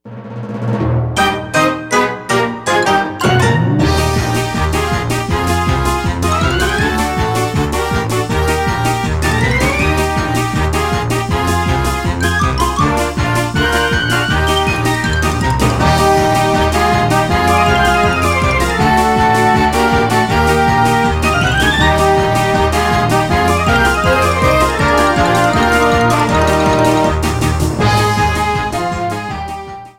Faded in the end